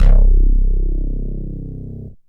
SYNTH LEADS-1 0004.wav